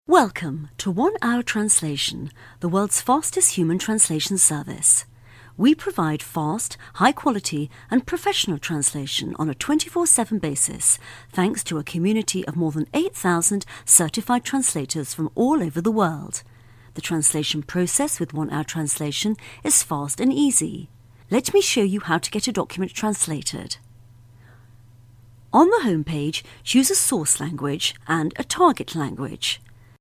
Sprechprobe: eLearning (Muttersprache):
Very easy to work with, takes direction very well, adaptable, flexible, diverse, range of styles from Warm, Friendly, Sexy to Serious, Sad, Corporate.